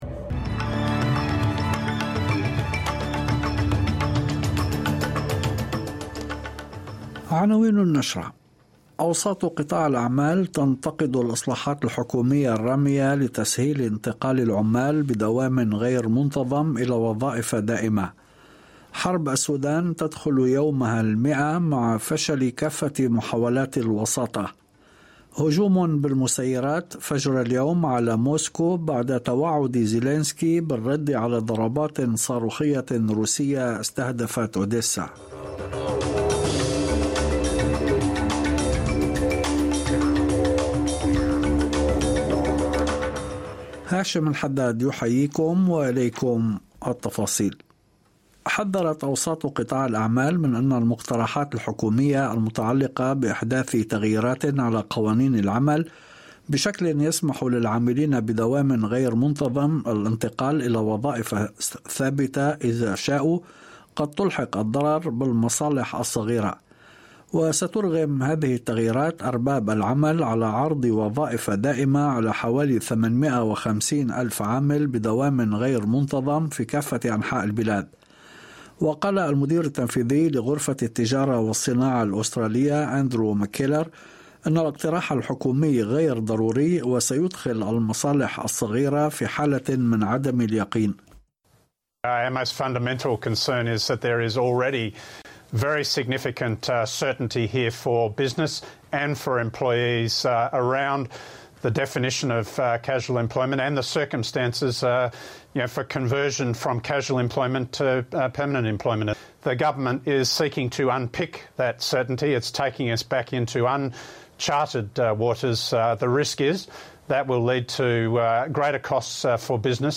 نشرة أخبار المساء 24/07/2023